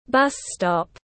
Điểm dừng xe buýt tiếng anh gọi là bus stop, phiên âm tiếng anh đọc là /ˈbʌs ˌstɒp/.
Bus stop /ˈbʌs ˌstɒp/